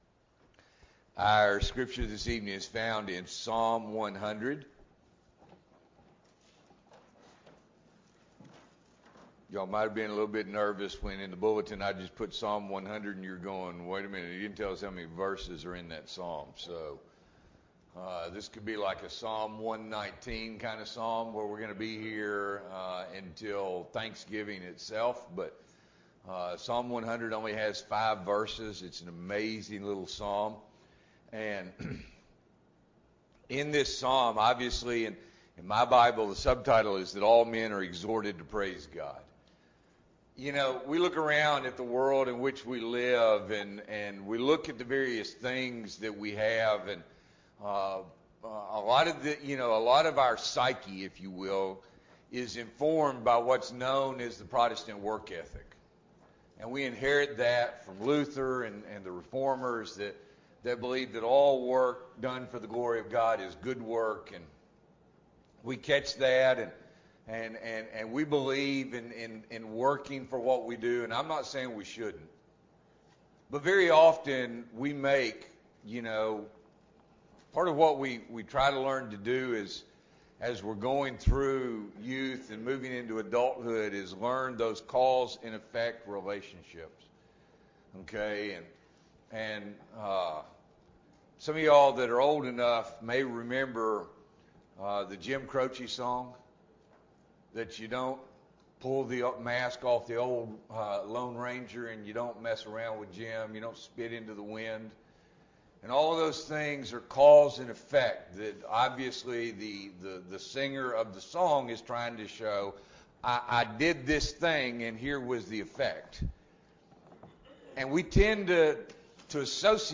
November 14, 2021 – Evening Worship